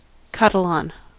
Ääntäminen
Synonyymit Catalonian Ääntäminen US Tuntematon aksentti: IPA : /ˈkæ.tə.ˌlæn/ Haettu sana löytyi näillä lähdekielillä: englanti Määritelmät Substantiivit Person from or inhabitant of Catalonia .